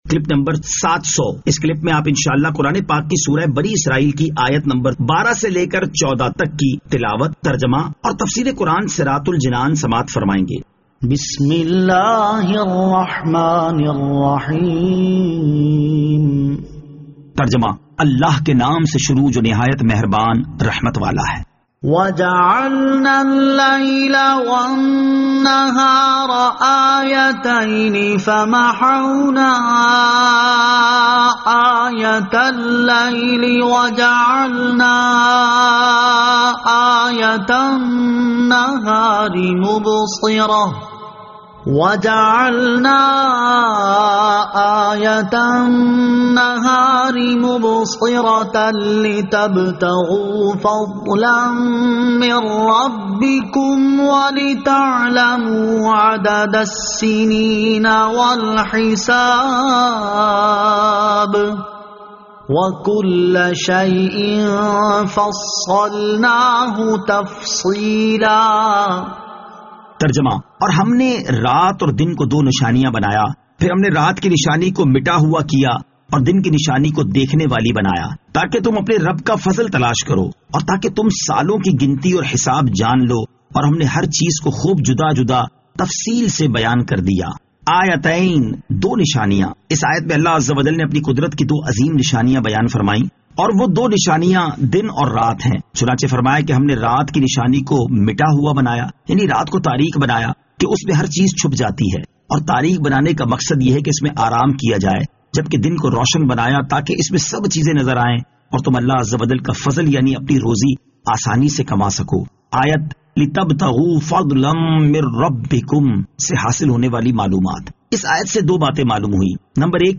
Surah Al-Isra Ayat 12 To 14 Tilawat , Tarjama , Tafseer